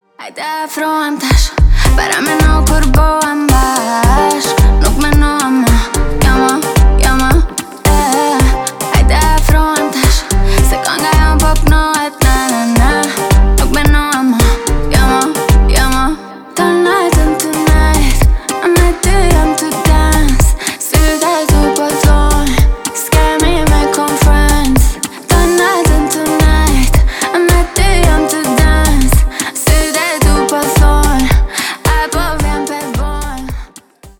Рэп и Хип Хоп # латинские